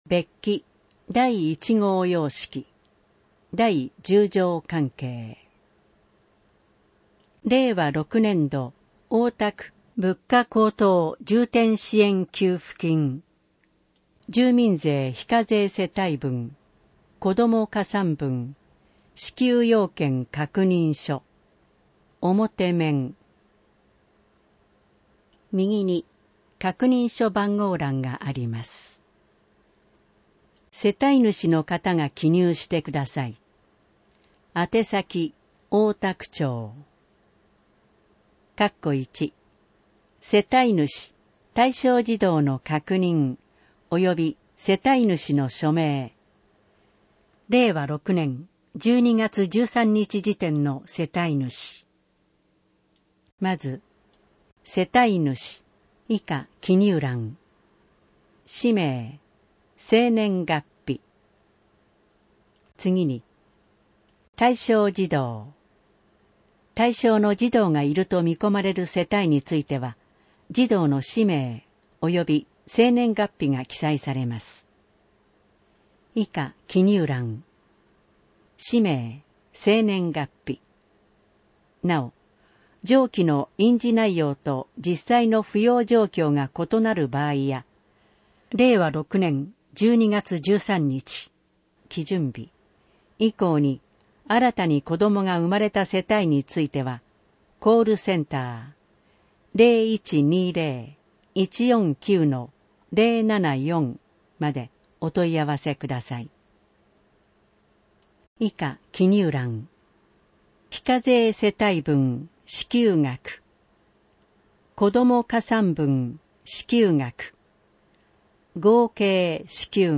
音声版
なお、この音声版は、障がい者総合サポートセンター声の図書室で製作したCDを再生したものです。